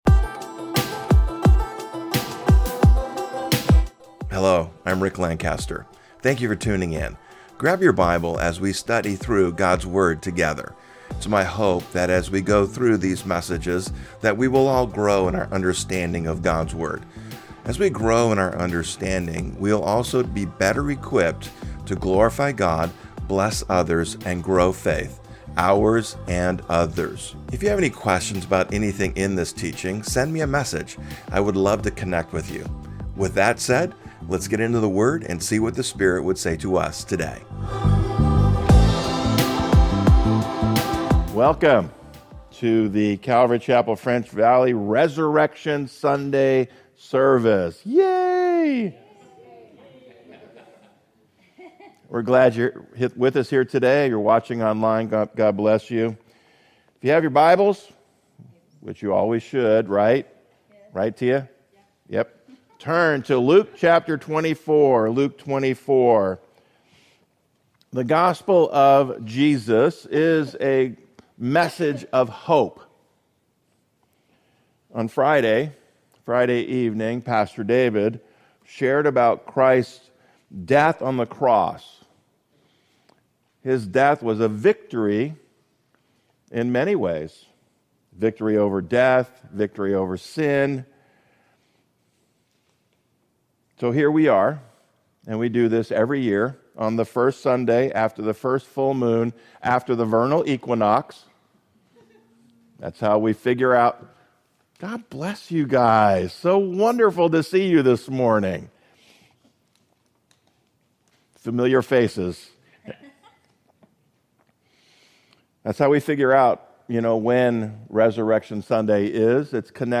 From Series: "Topical Messages"